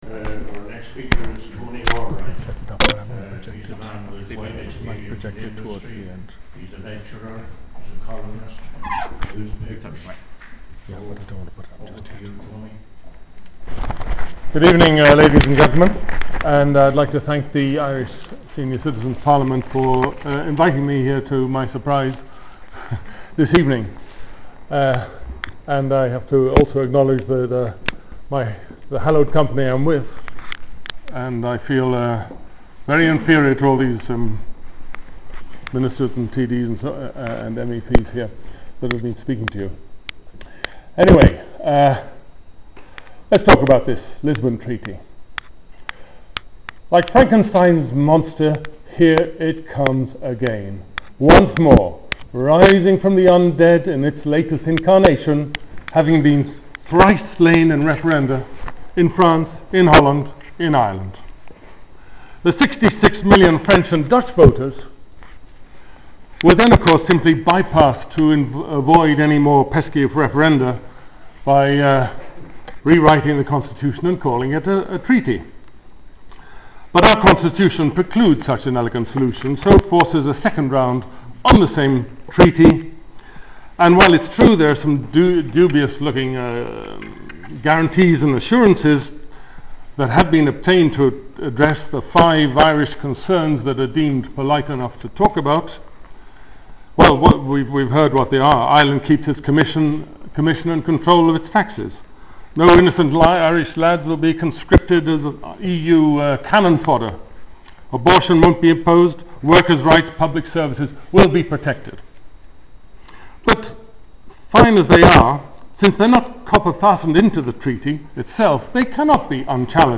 You can listen to a summary of this post as a Podcast, as presented to the Irish Senior Citizens Parliament on 9 September 2009